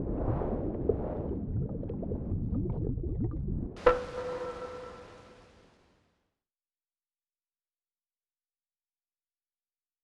water reload 2.wav